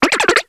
Grito de Scatterbug.ogg
Grito_de_Scatterbug.ogg